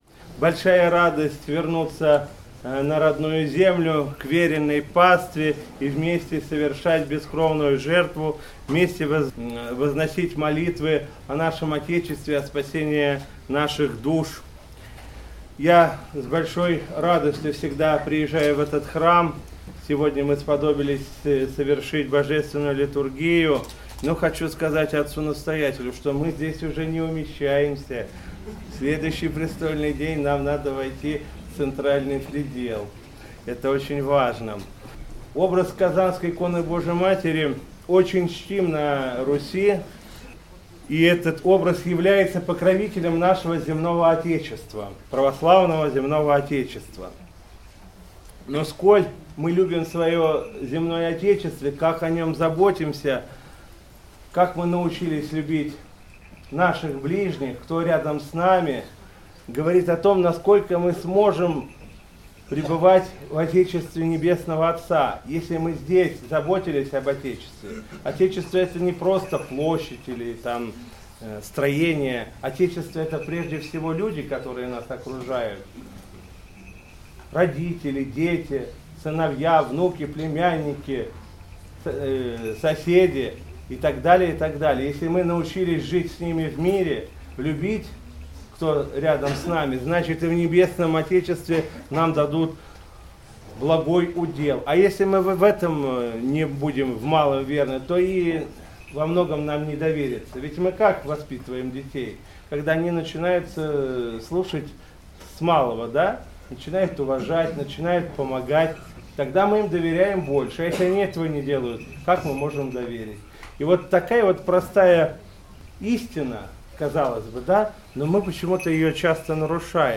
По окончании богослужения владыка Игнатий с сослужащими священниками совершил славление Пресвятой Богородице и обратился к присутствующим с архипастырским словом, поздравив всех с престольным праздником восстанавливаемого Казанского храма.